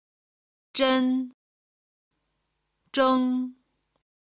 「zhen」と「zheng」の中国語ピンイン音の比較